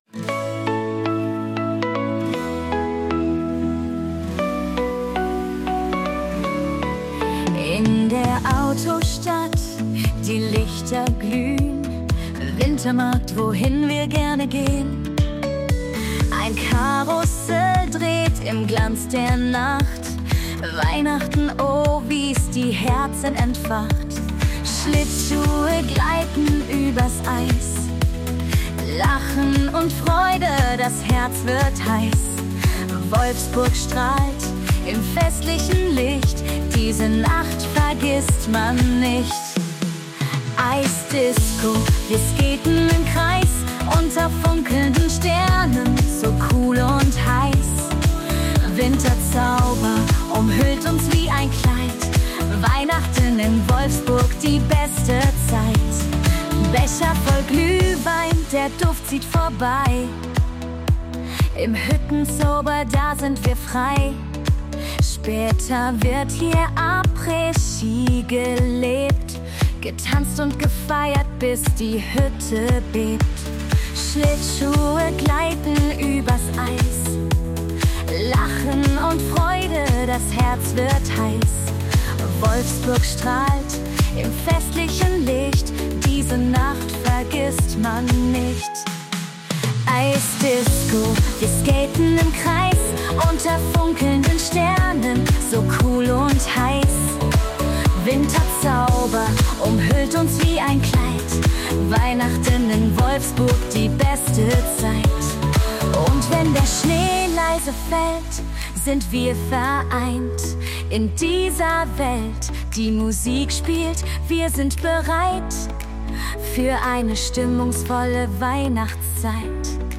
Gute Laune mit Ohrwurmgarantie
KI-generiert